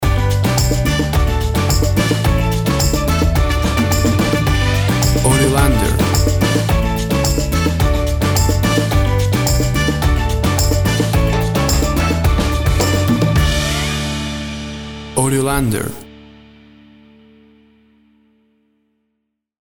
Tempo (BPM) 109